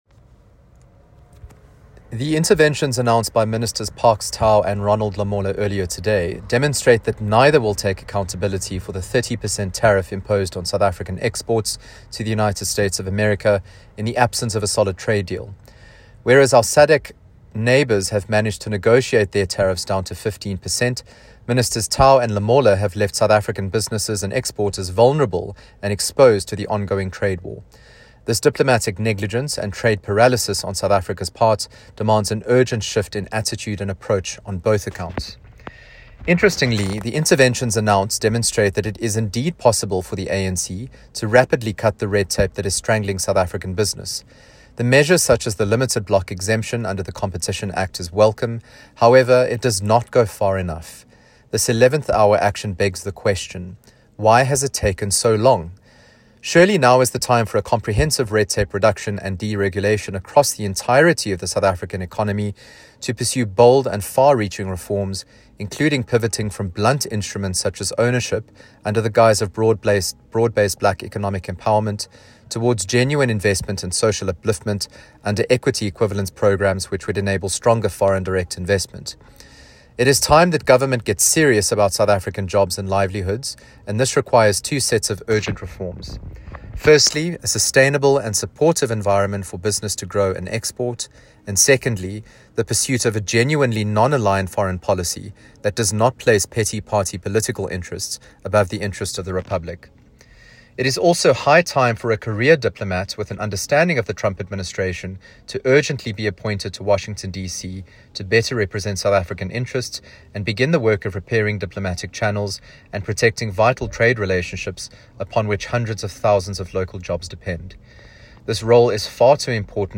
soundbite by Ryan Smith MP